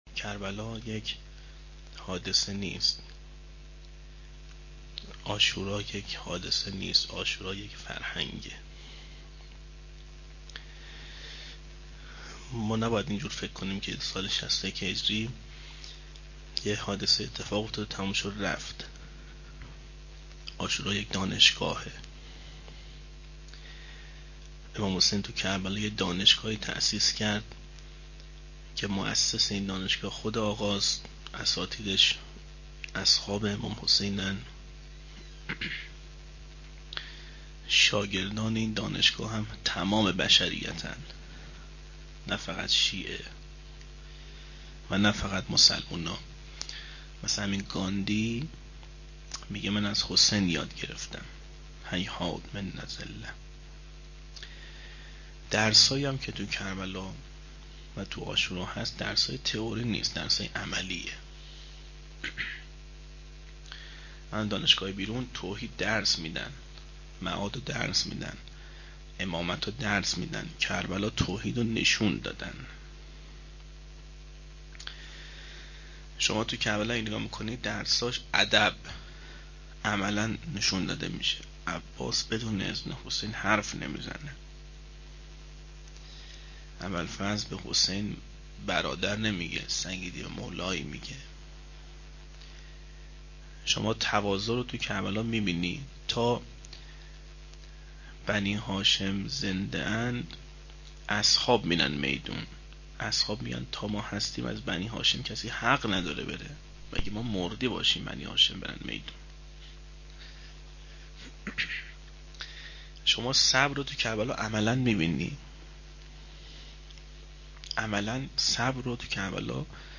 01-shab7-sokhanrani.mp3